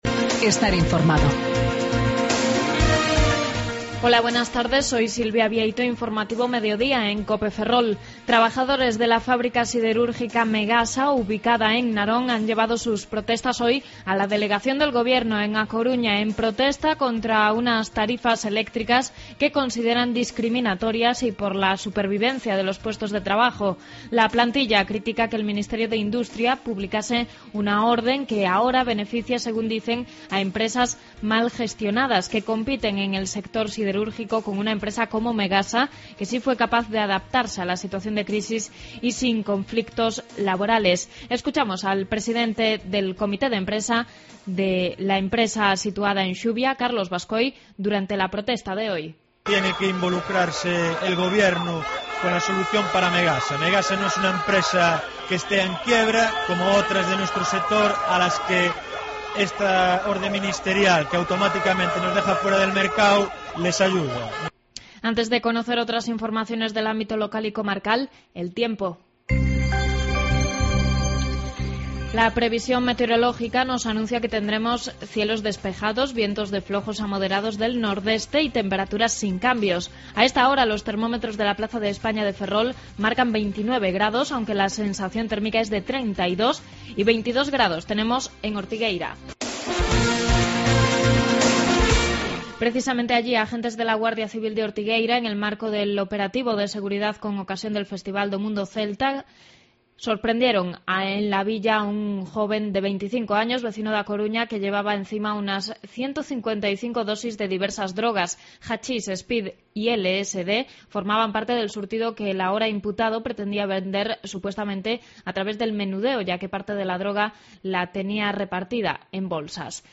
14.25 Informativo Mediodía Cope Ferrol